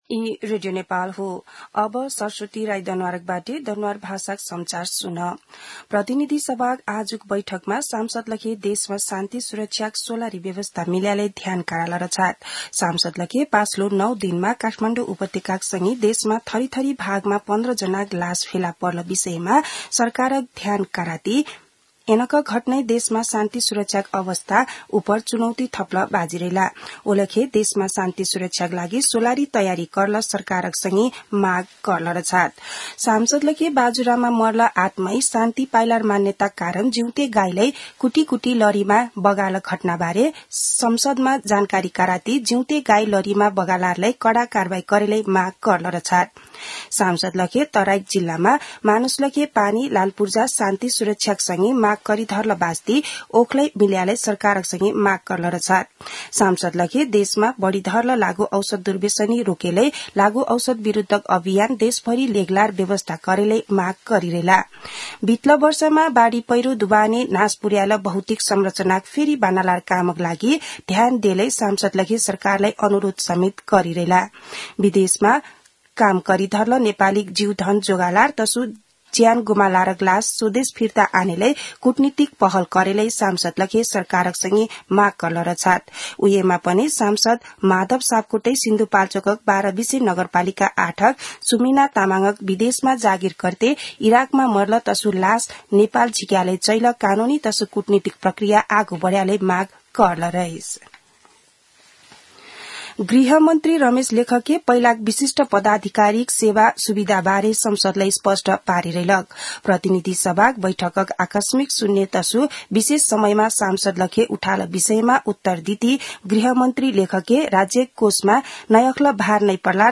दनुवार भाषामा समाचार : ४ चैत , २०८१
danuwar-news-1-6.mp3